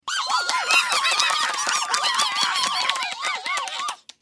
دانلود آهنگ میمون 9 از افکت صوتی انسان و موجودات زنده
دانلود صدای میمون 9 از ساعد نیوز با لینک مستقیم و کیفیت بالا
جلوه های صوتی